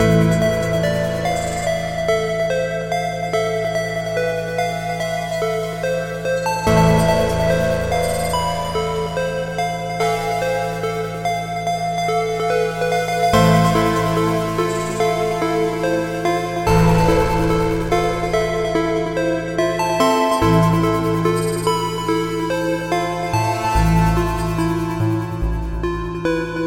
Tag: 144 bpm Trap Loops Synth Loops 4.49 MB wav Key : A